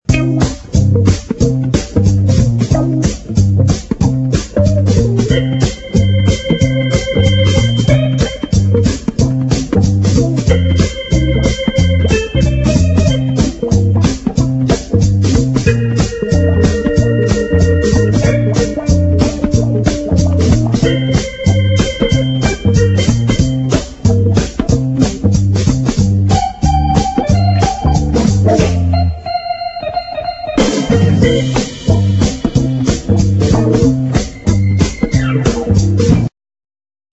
funny medium instr.